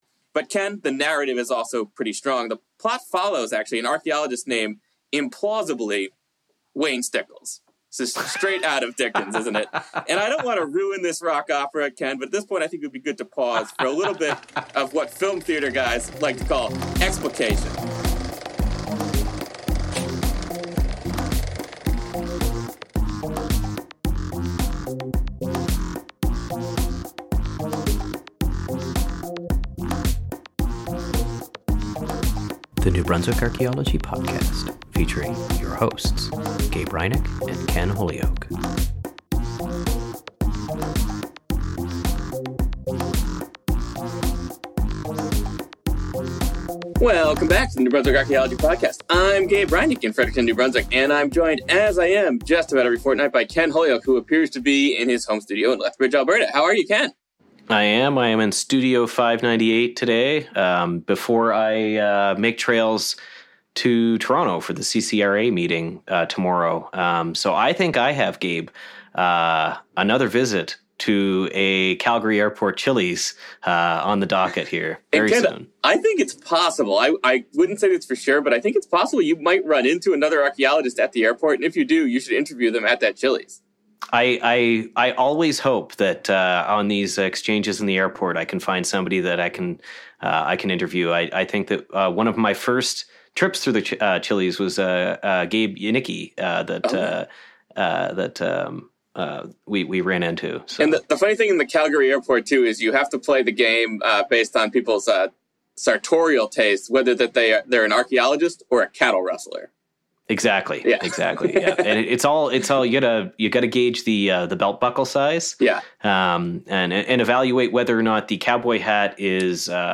an interview with the phenom of phenomenology himself